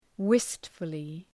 Προφορά
wistfully.mp3